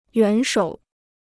元首 (yuán shǒu) Kostenloses Chinesisch-Wörterbuch